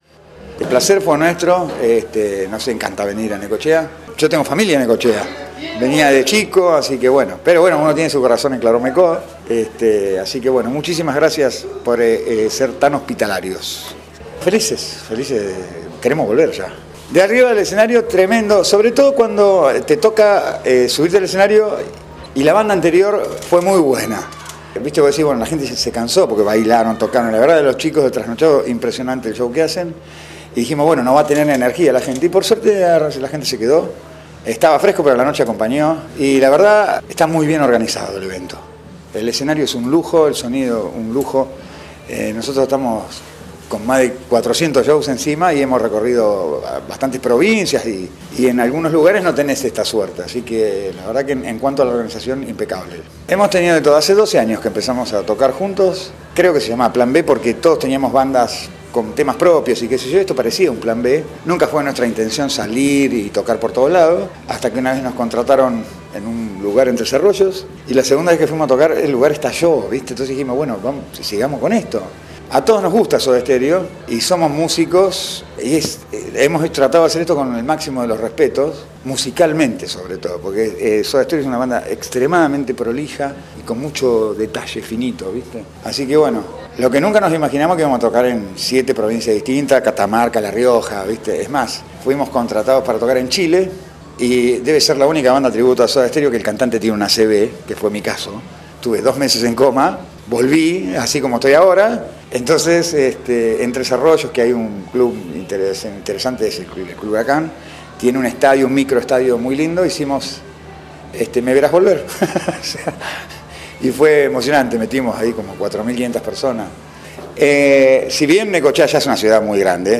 en el marco de los festejos por el 143º Aniversario de Necochea en la Plaza Dardo Rocha
rock y pop